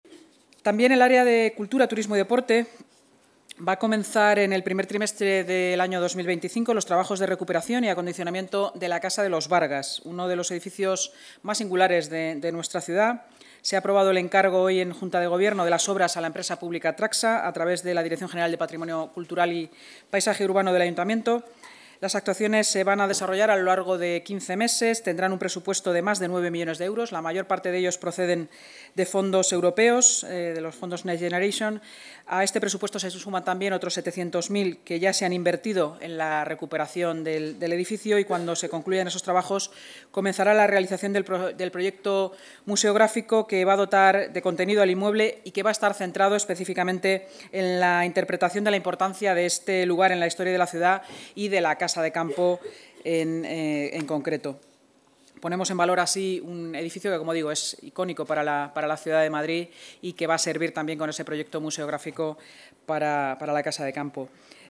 Nueva ventana:Inma Sanz, portavoz municipal